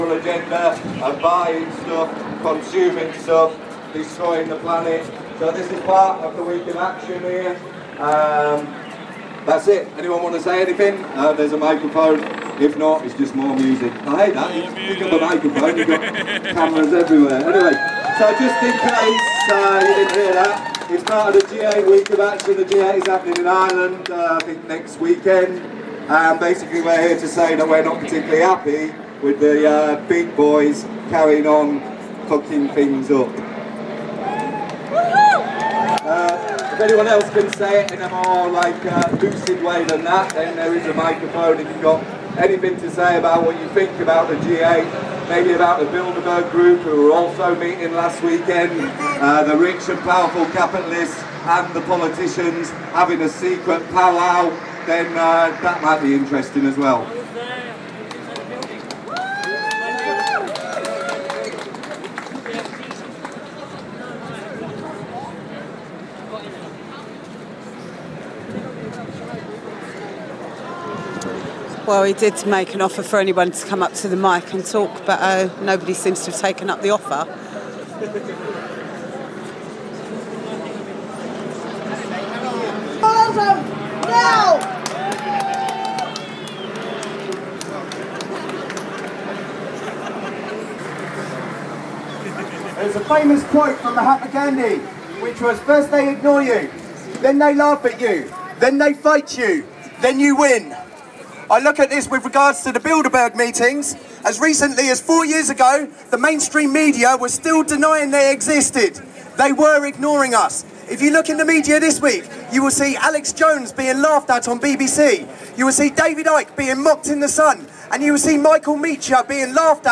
Protesters talking about why they have come today